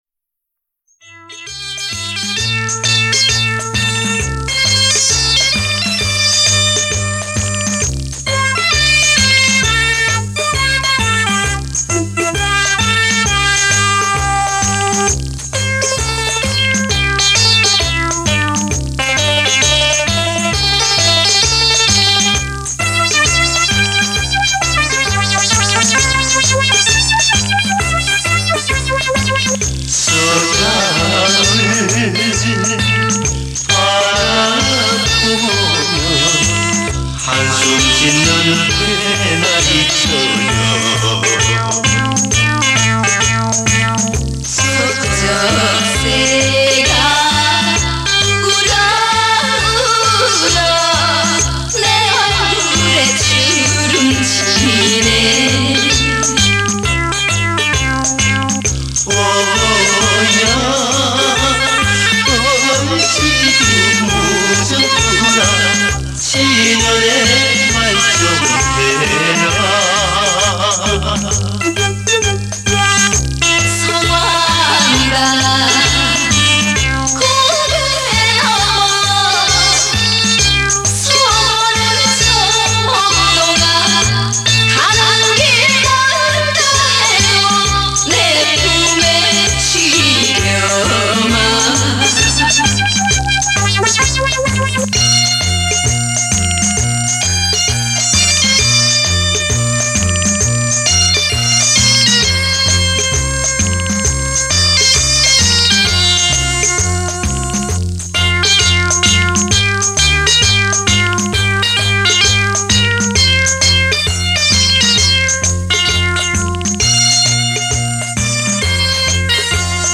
[23/12/2009]南韩演歌：再来一小段正宗的南韩小调（男女对唱）80年代初的联唱歌曲（调好听）
这回截两首歌曲：一首是后半部另一首是前半部。
这盘联唱歌曲是我以前在数码音乐室用磁代花钱转的。
韩国演歌音乐环绕效果特别强！~